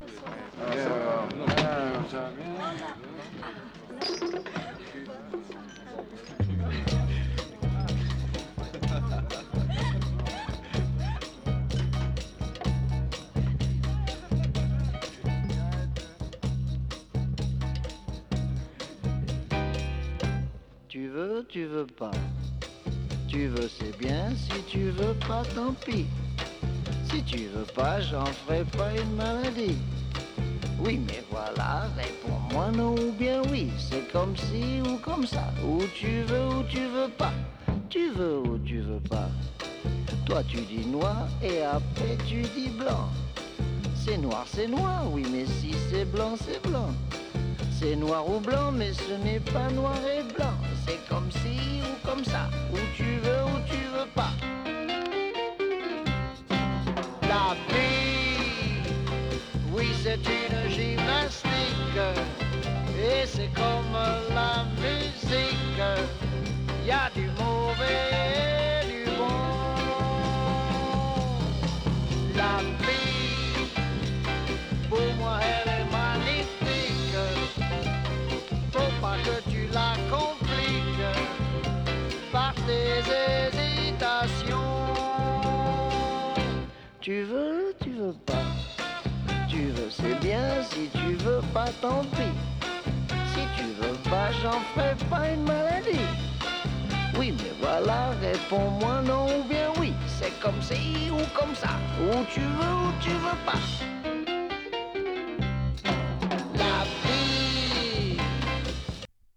フレンチ ポップ